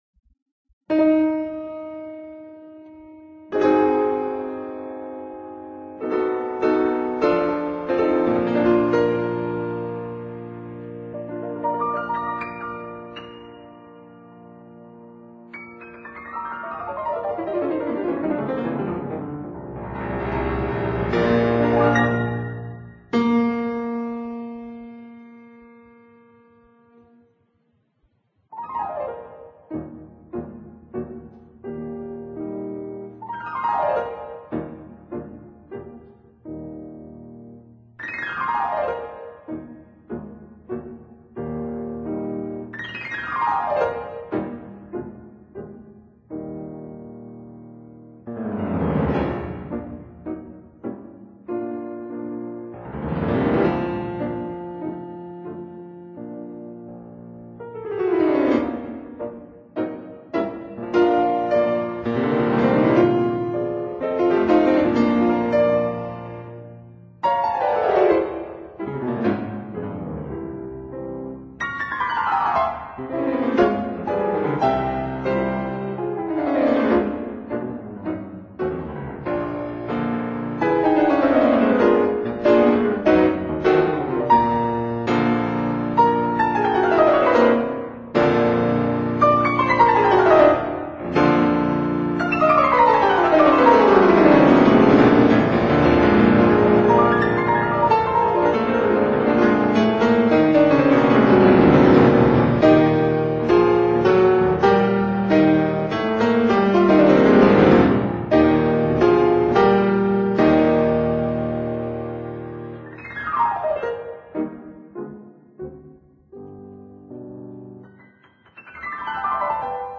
Alfred Brendel, Piano